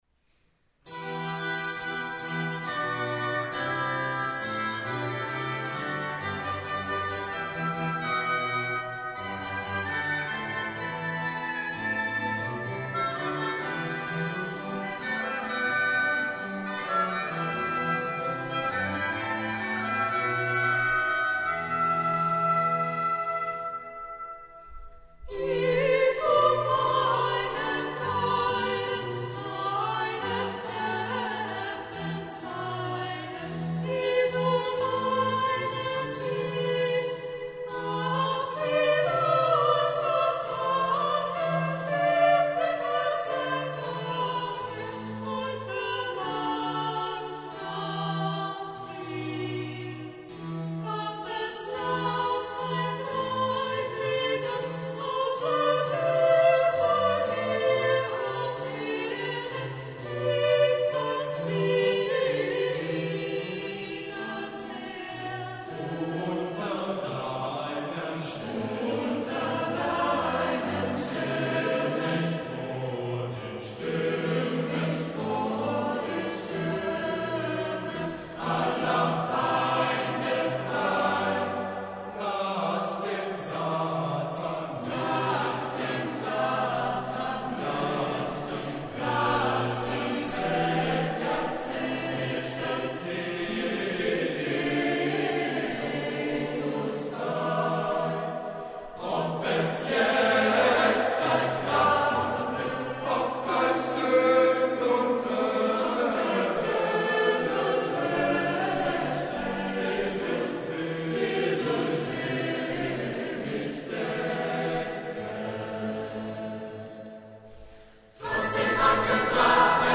in der Entenfußhalle des Klosters Maulbronn
Kantate für Soli, Chor und kleines Orchester